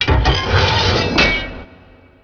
gearopen.wav